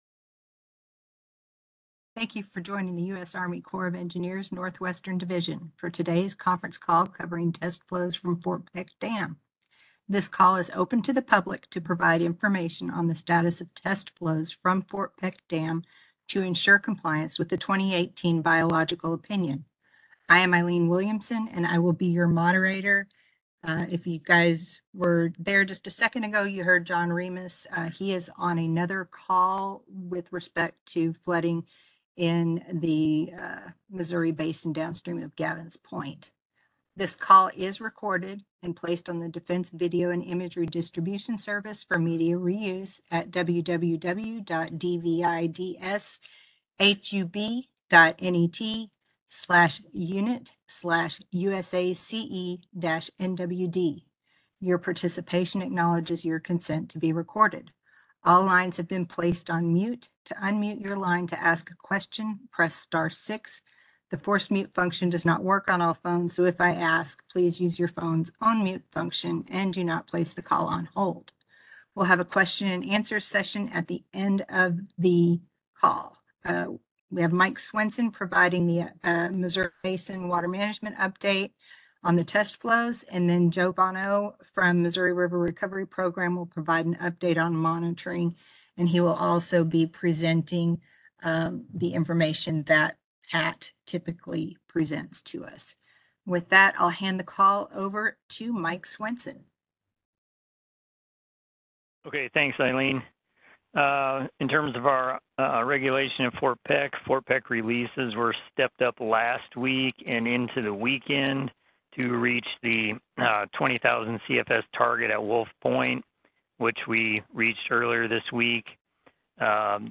Gavins Point Dam releases remain at 13,000 cfs. Spokespersons from state and local Emergency Response functions join the discussion.